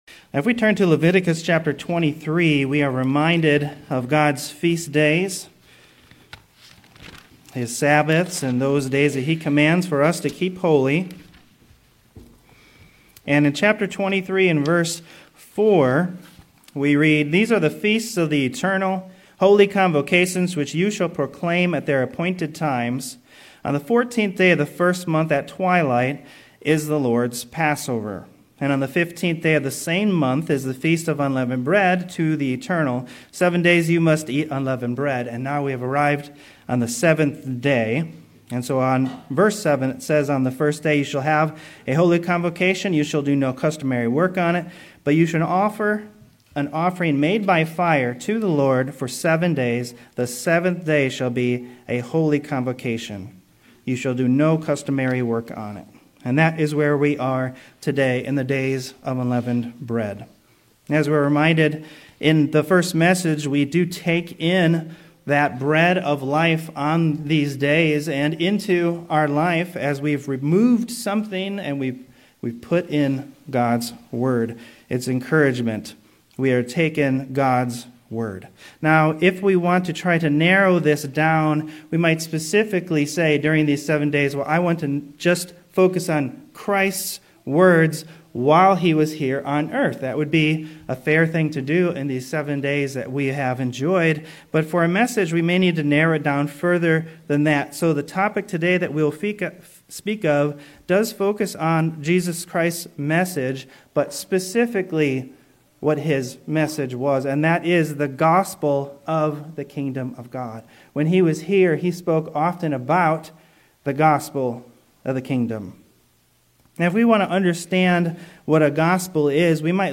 Sermons
Given in Cleveland, OH North Canton, OH